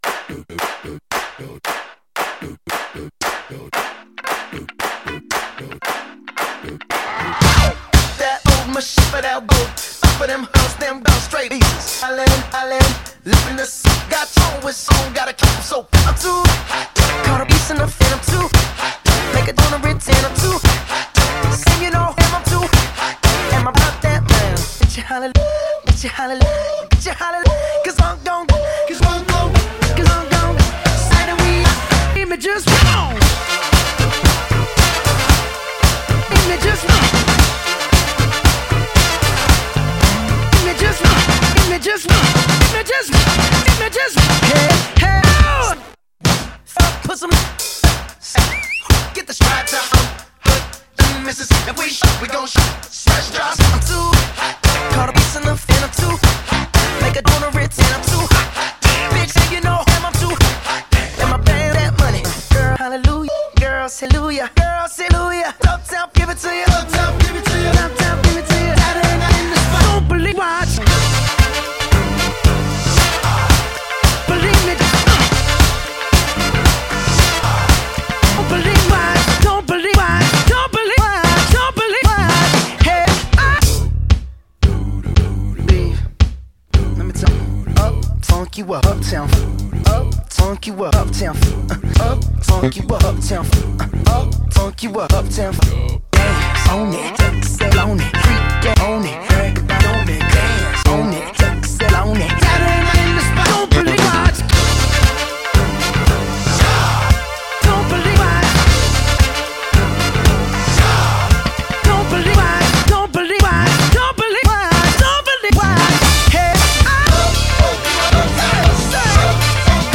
absolutely banging funk instrumental